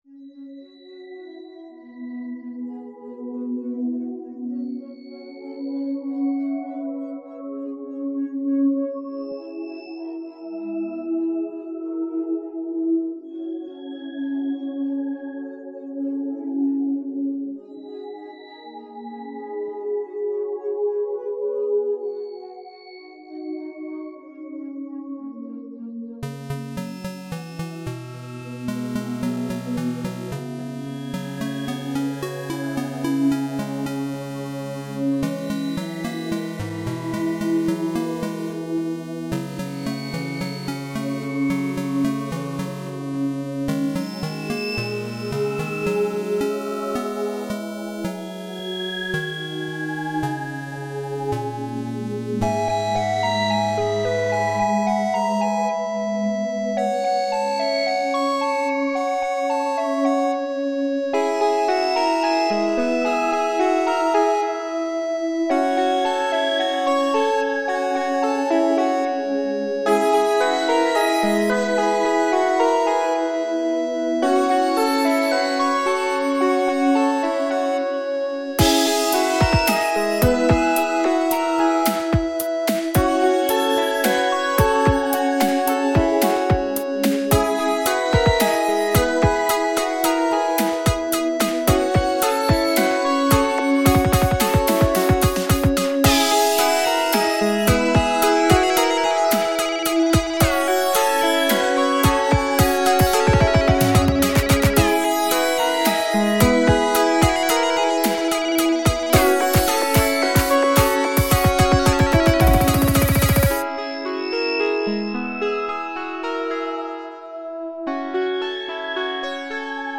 I'd made part of this song a really long time ago, and finally just decided to finish it. Not the greatest song, but it does have a nice space-y, atmospheric, feel.